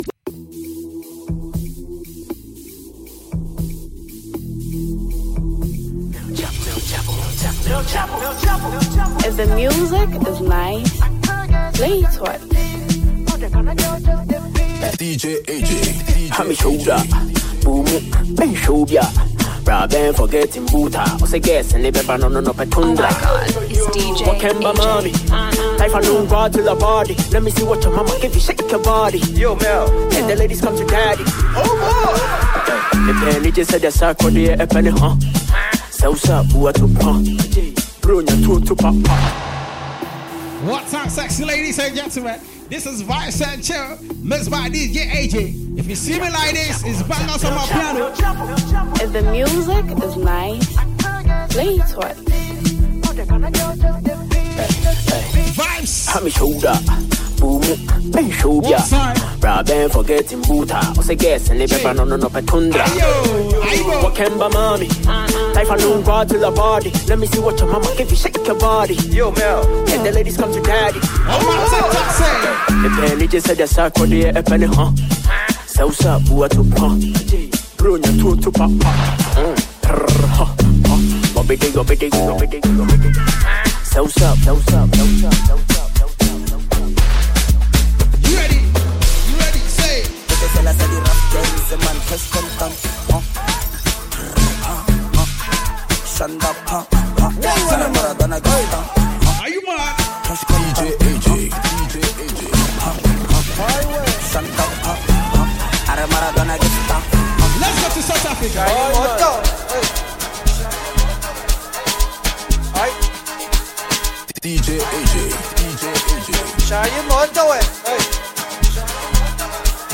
Afrobeat and amapiano